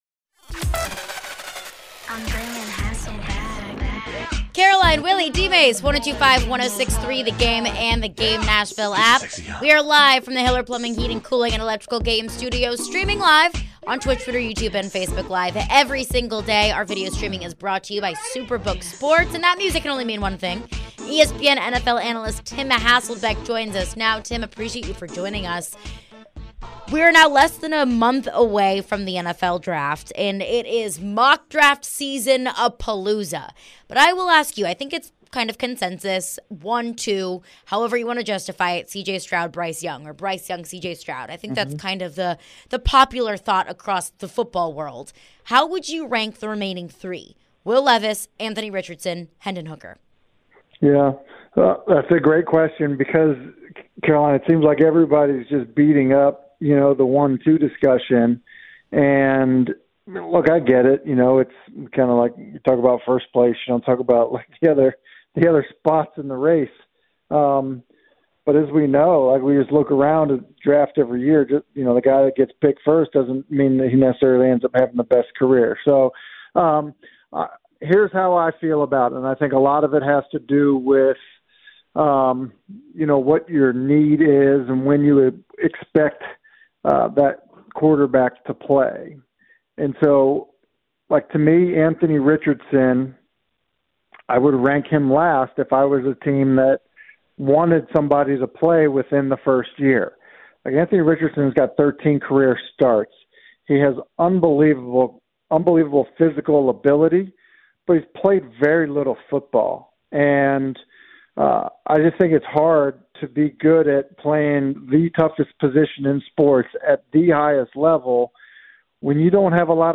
Tim Hasselbeck Interview (3-28-23)
ESPN NFL Analyst Tim Hasselbeck joins for his weekly visit & touches on the Lamar Jackson news & more!